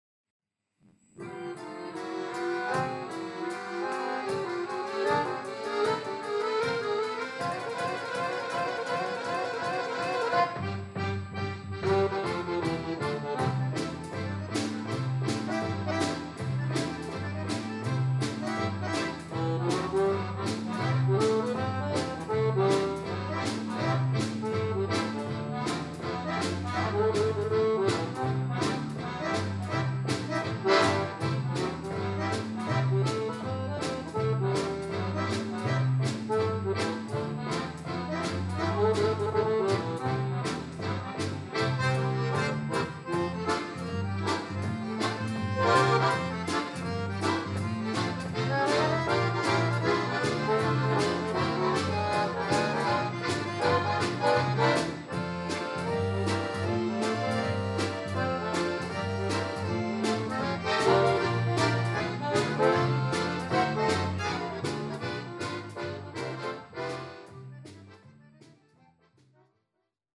Konzert 2006 -Download-Bereich
-------Das Orchester-------